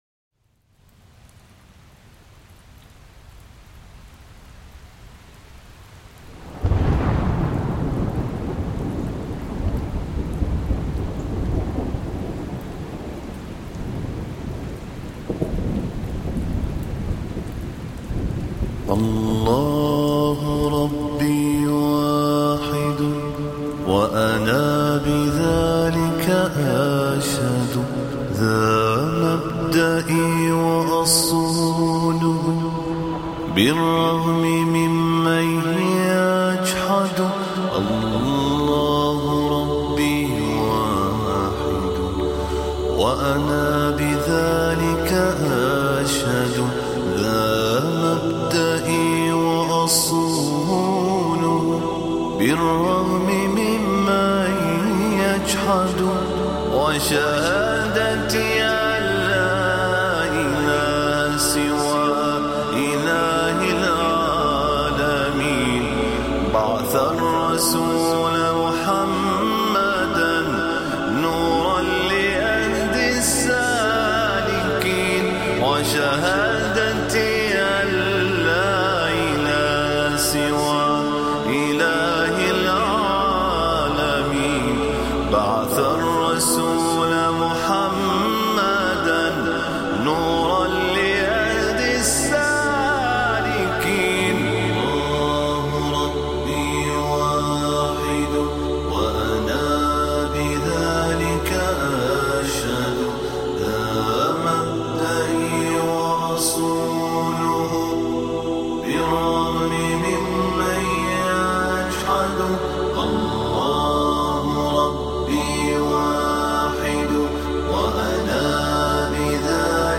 المنشد الدكتور